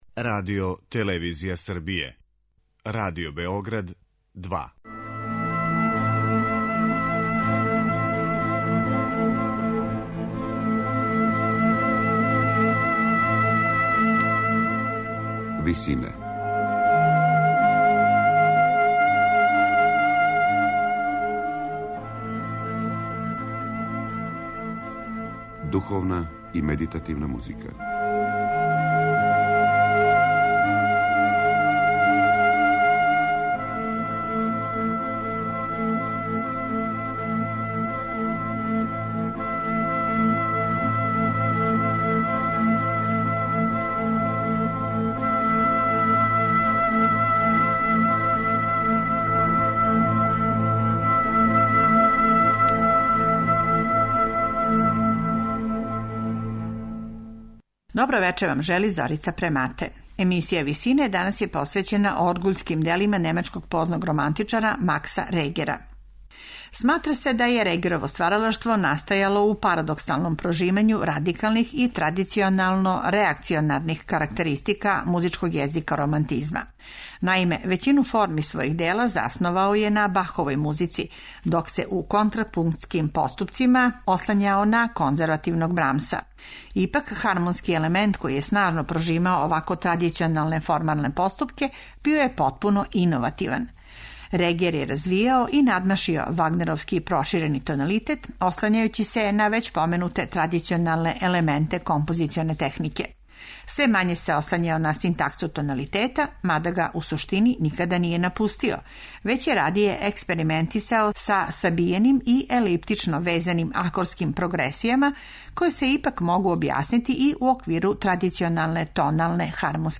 медитативне и духовне композиције
на историјском инструменту катедрале у Лудвигсбургу